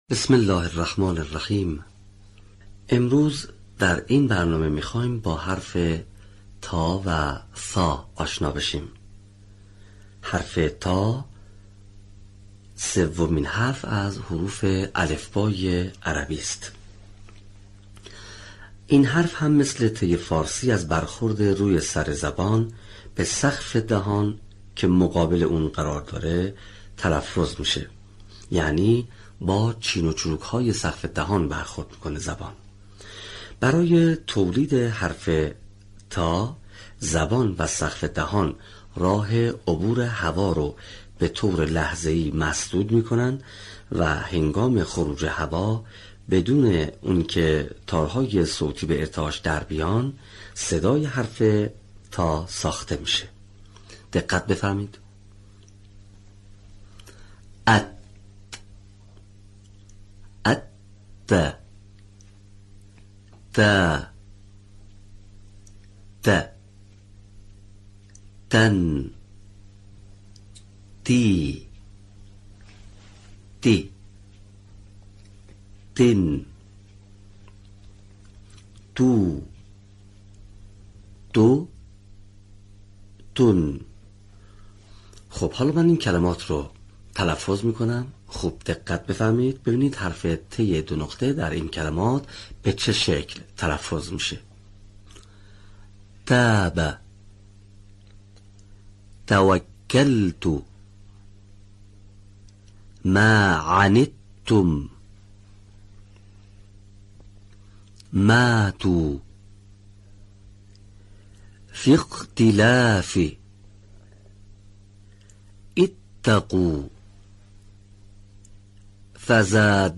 صوت | تلفظ صحیح حروف «ط» و «ث»
به همین منظور مجموعه آموزشی شنیداری (صوتی) قرآنی را گردآوری و برای علاقه‌مندان بازنشر می‌کند.